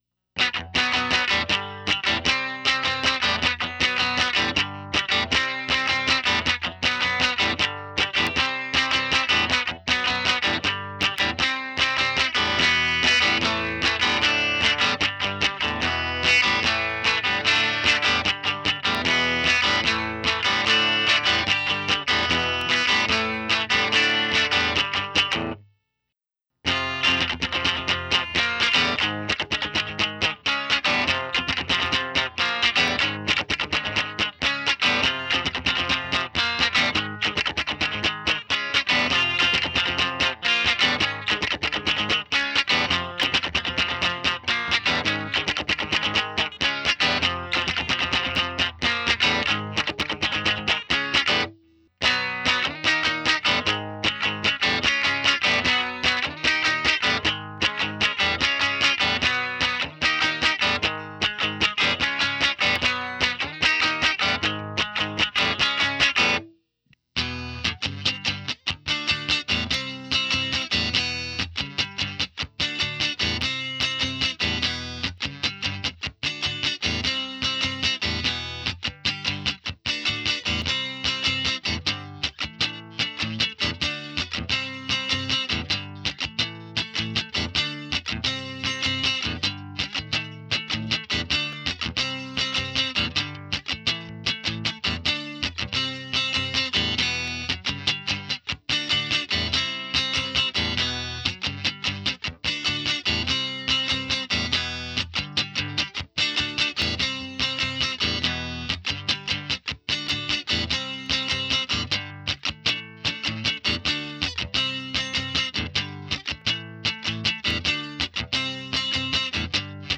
Twin Reverb On its 'Back Legs' so its angled towards the ceiling/wall and (Amp is facing the wall because I heard that creates more lowends) Guitar straight into the Twin without any pedals. Single SM57 going into the Scarlett. This was also recorded using an Fender Strat with a 59n in the bridge.